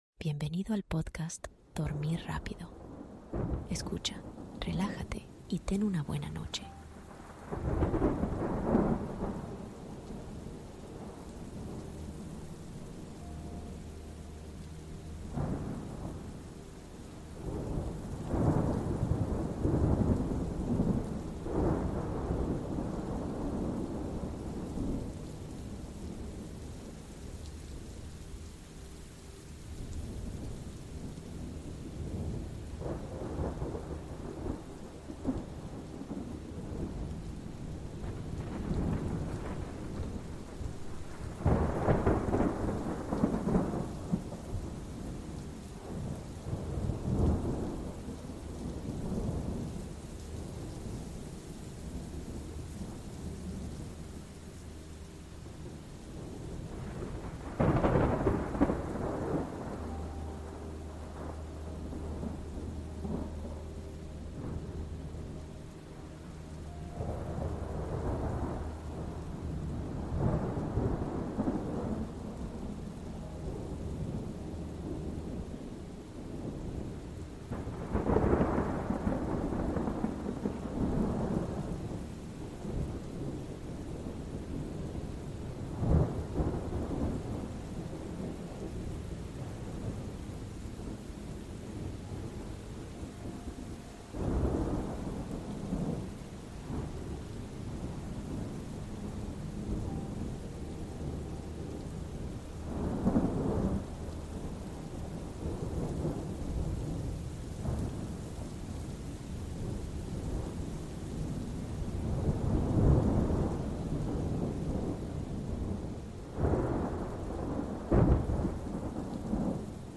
⛈ MÚSICA Tranquila y TORMENTA Melodiosa: BIENESTAR y SUEÑO de Calidad